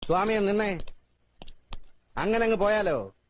Comedy Ringtones Dialogue Ringtones